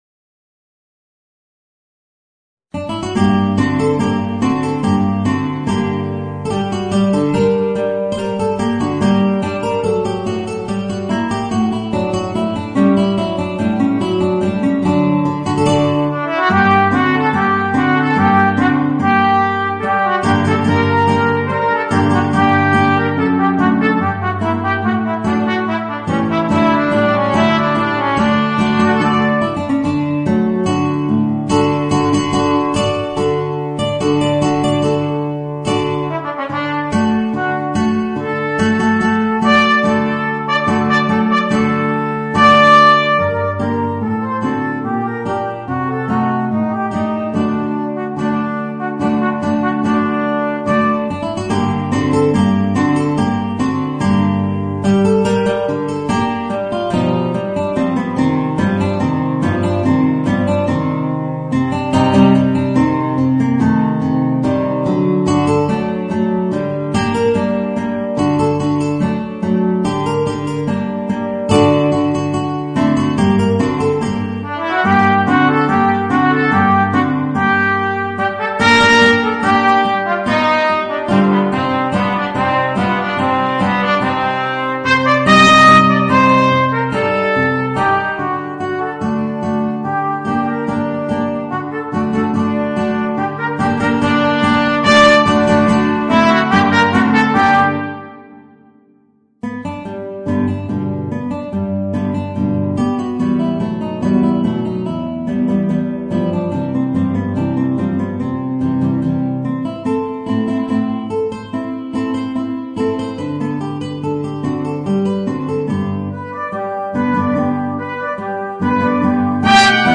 Voicing: Guitar and Trumpet